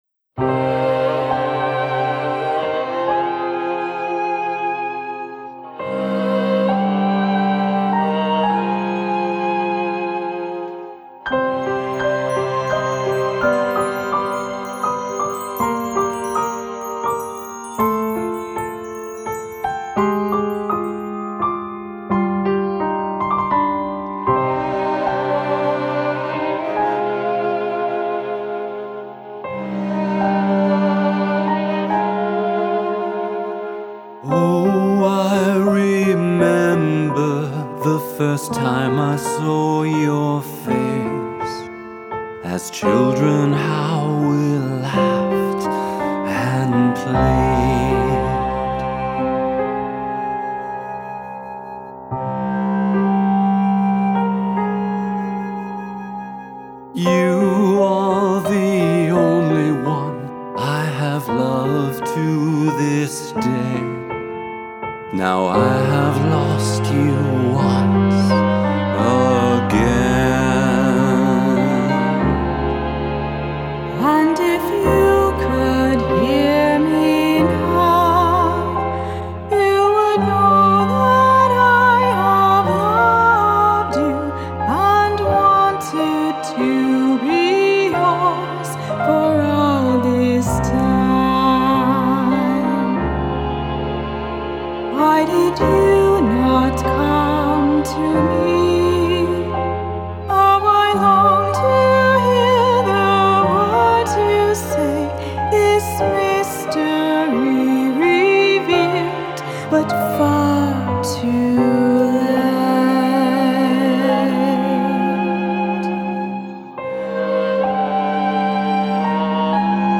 A Gothic Drama
Solo violin and viola